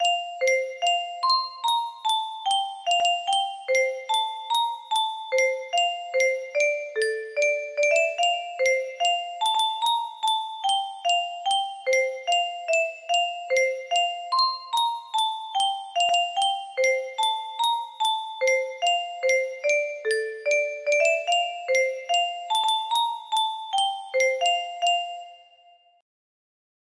Little Sweet Dream music box melody